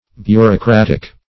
Bureaucratic \Bu`reau*crat"ic\, Bureaucratical